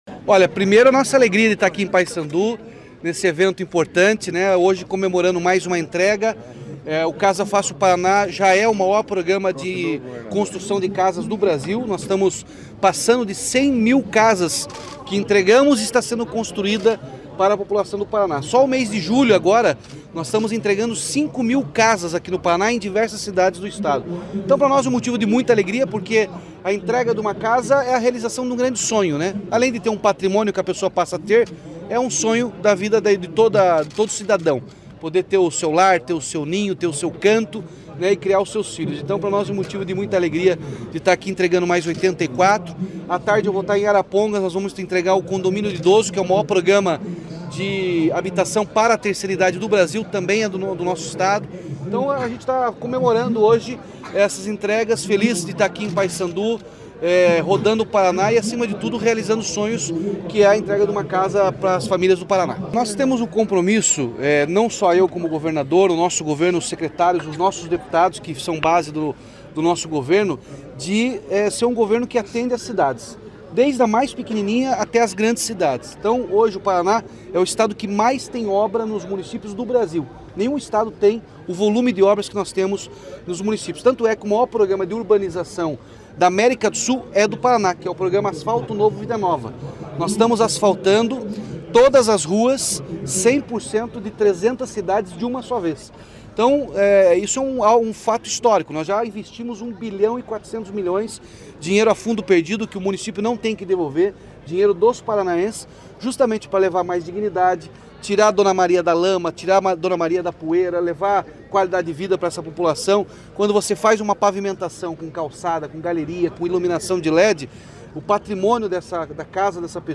Sonora do governador Ratinho Junior sobre a entrega de residencial com 84 moradias em Paiçandu | Governo do Estado do Paraná